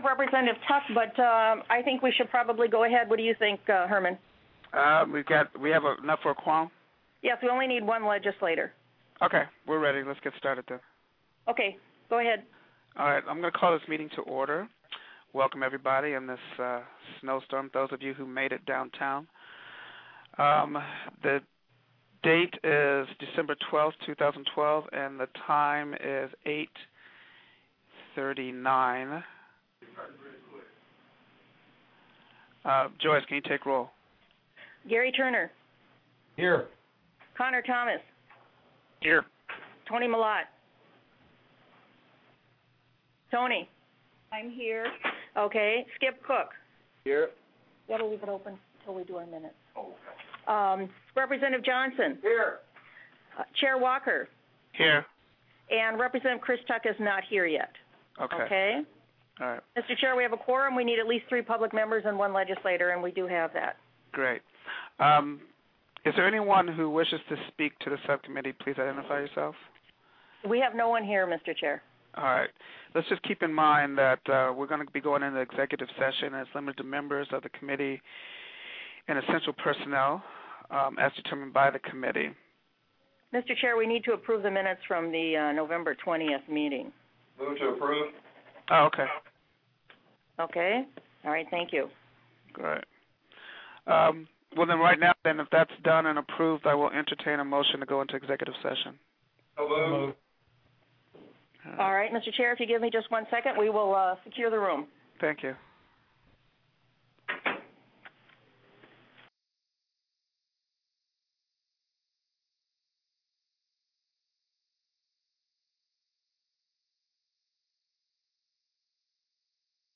12/12/2012 08:30 AM House SELECT COMMITTEE ON LEGISLATIVE ETHICS
Open Session TELECONFERENCED